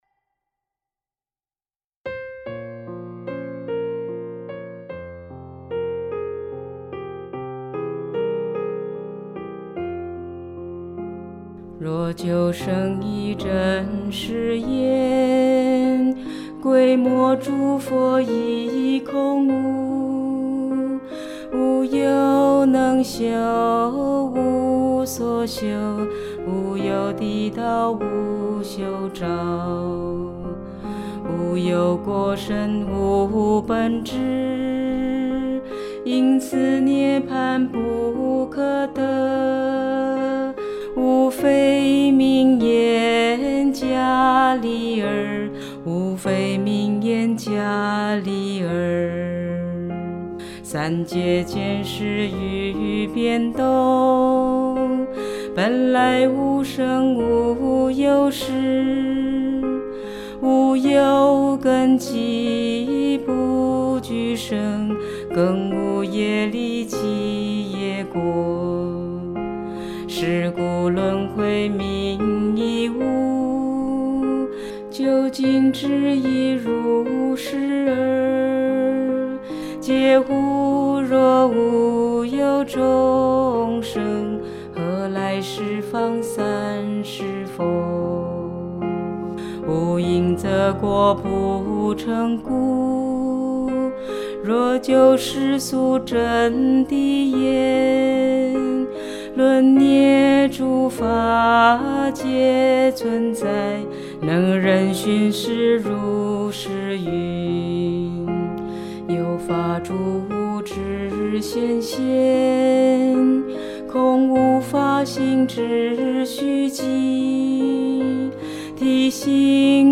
鋼琴配樂